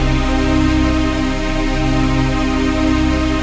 DM PAD2-46.wav